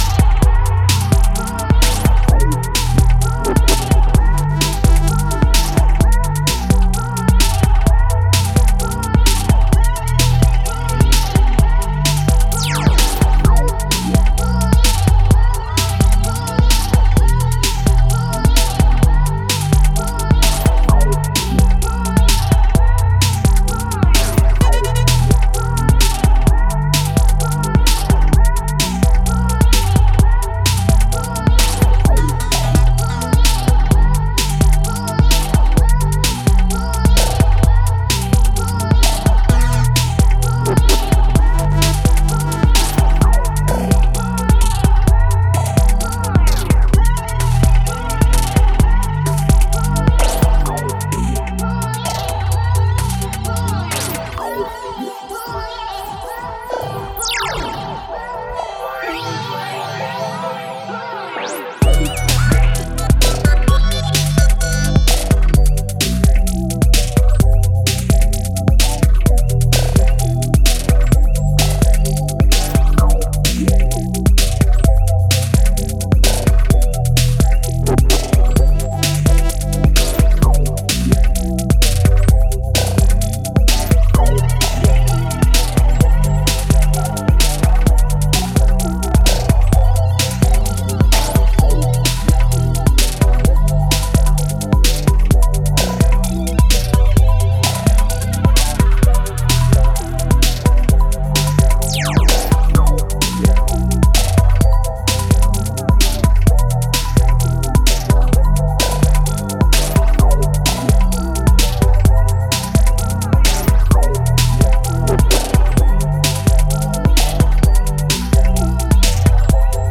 broken take on minimal